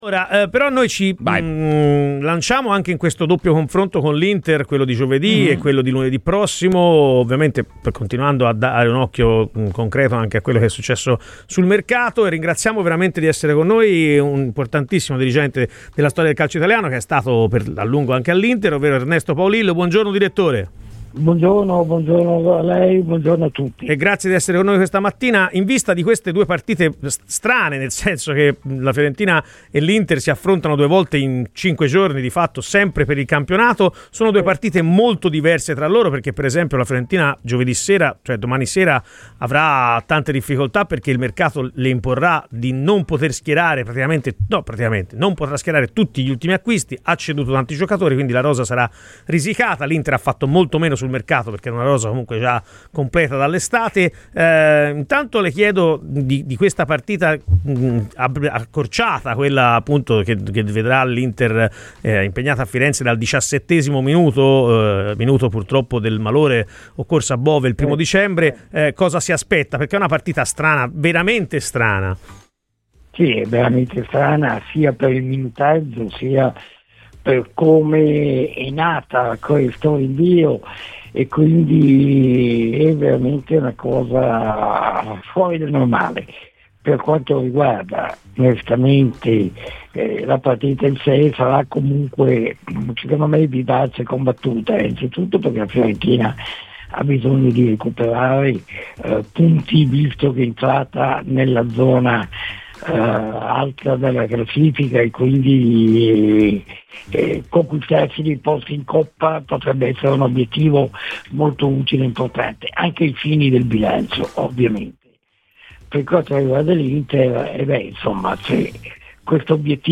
L’Inter può vincere la Champions, Marotta la meriterebbe” PER L’INTERVISTA COMPLETA ASCOLTA IL PODCAST